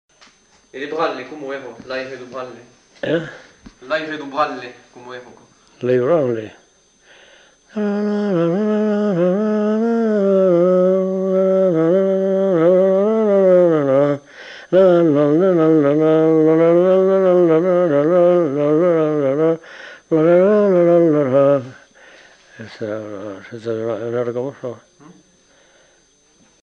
Branle de Lomagne (fredonné